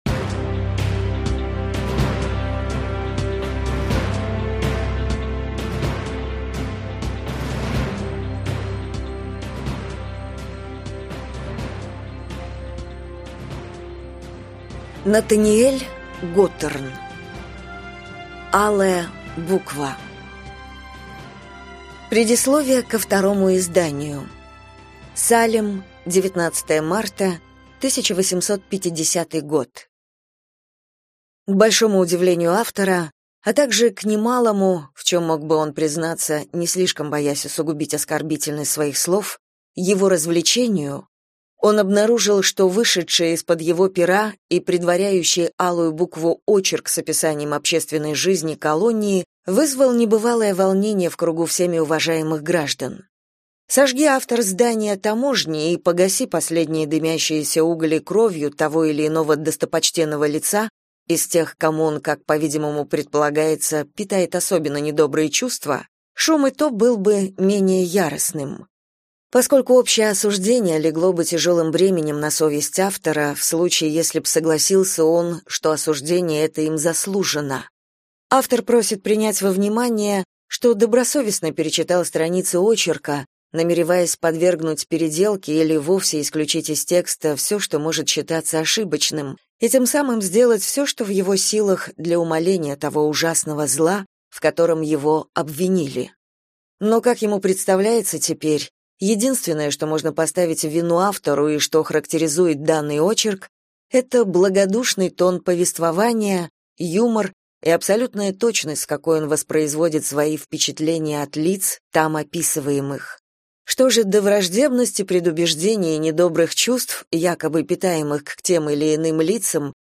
Аудиокнига Алая буква - купить, скачать и слушать онлайн | КнигоПоиск